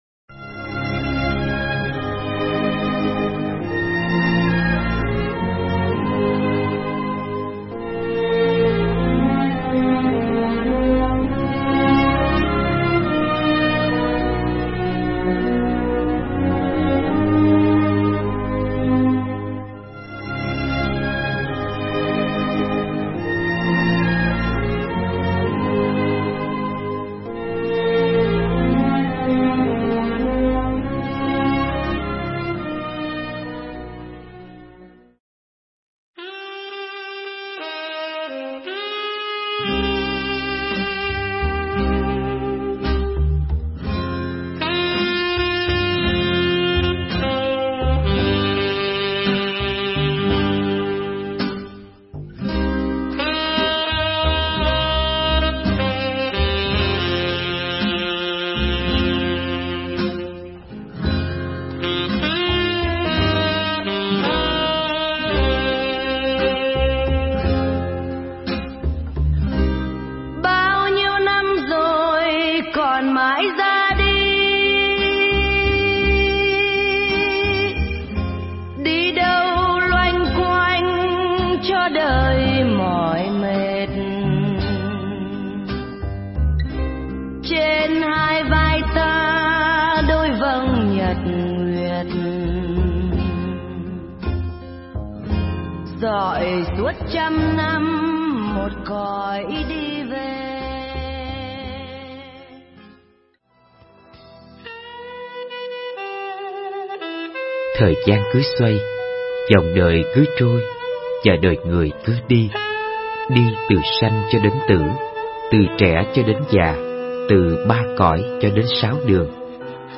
Nghe Mp3 thuyết pháp Bình Minh Xứ Sương Mù